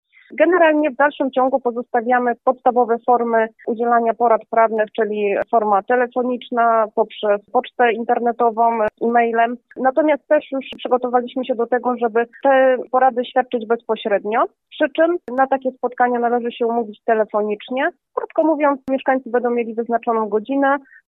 – Od 1 czerwca wracamy też po przewie do spotkań bezpośrednich z radcami prawnymi i adwokatami – mówi sekretarz powiatu zielonogórskiego Ewa Seremak: